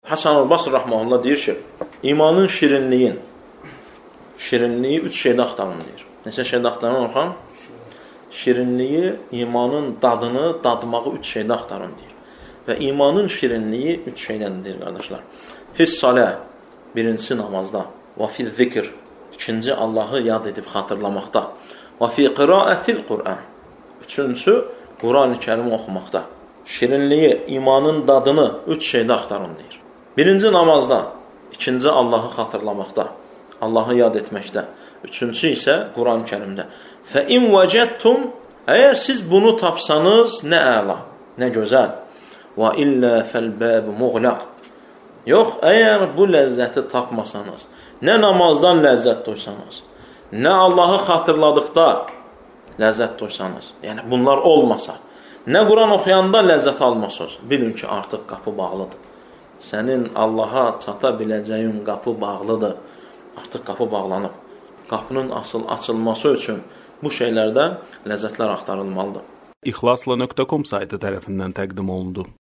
Dərslərdən alıntılar – 100 parça